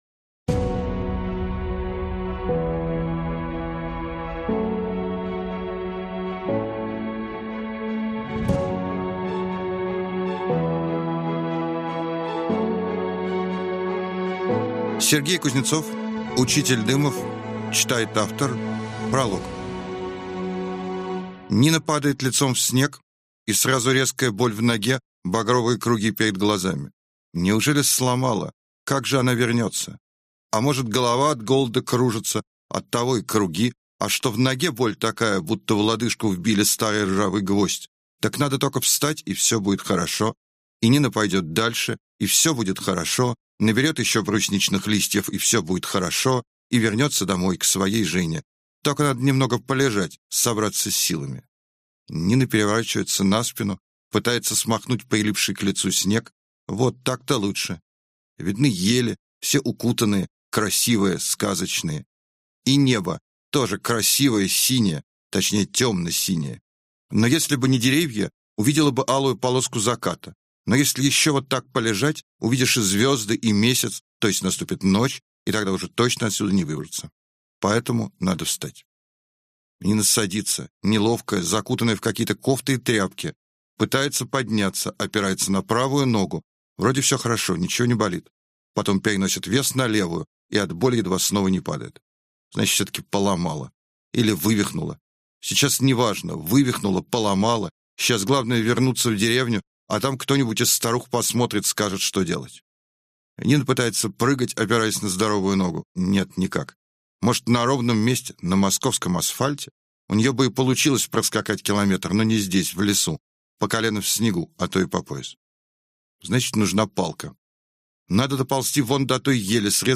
Прослушать фрагмент аудиокниги Учитель Дымов Сергей Кузнецов Произведений: 1 Скачать бесплатно книгу Скачать в MP3 Вы скачиваете фрагмент книги, предоставленный издательством